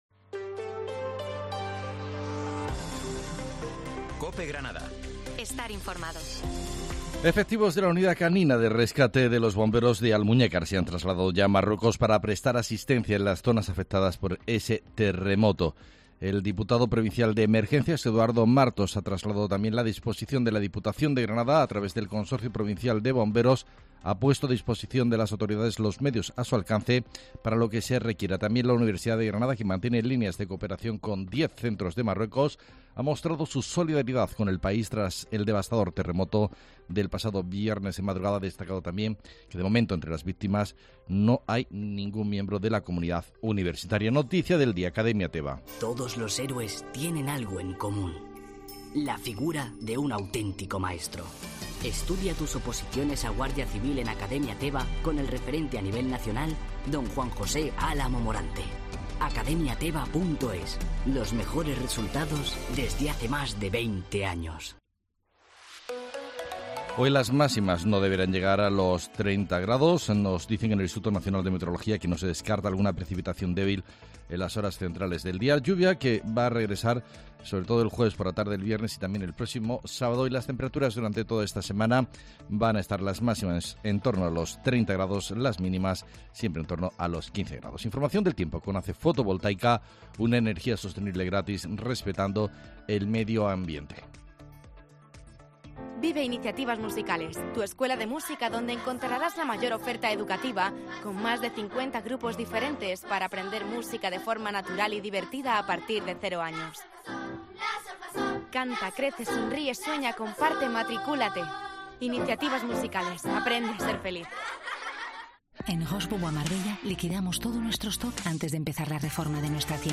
Herrera en Cope Granada, Informativo del 11 de septiembre